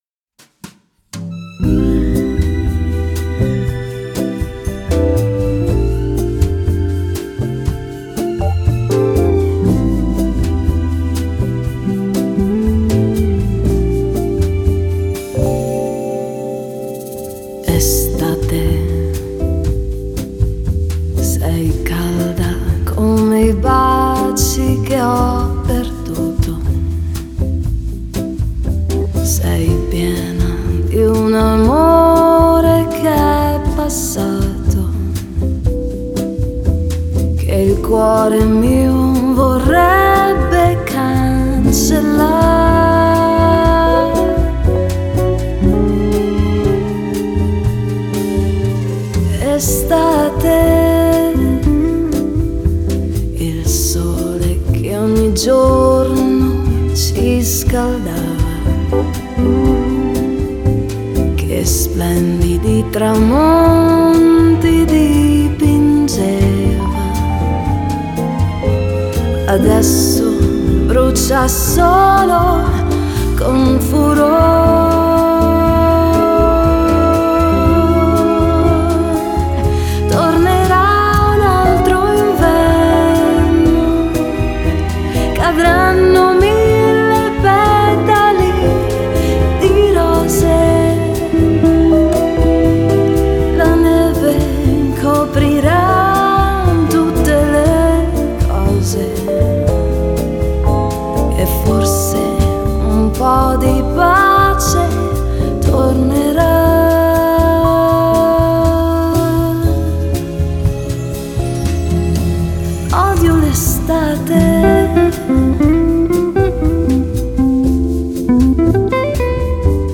Genre: Pop Lounge, Bossa